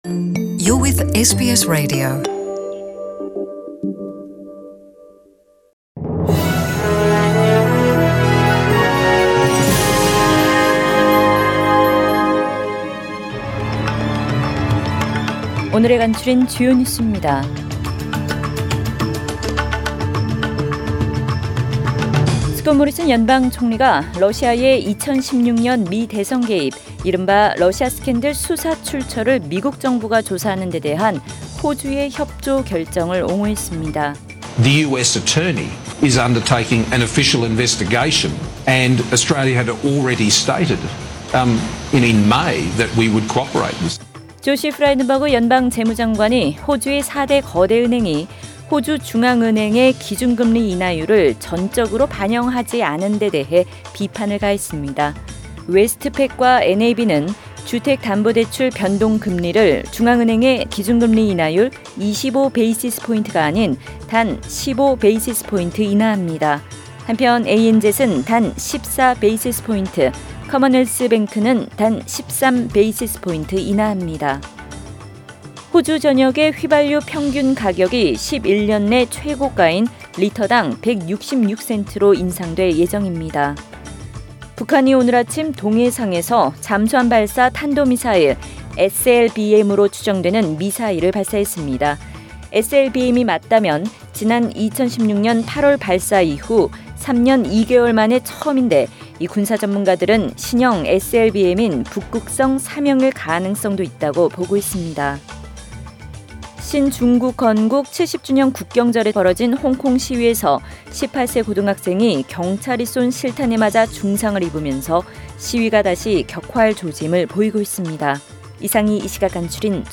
SBS 한국어 뉴스 간추린 주요 소식 – 10월 2일 수요일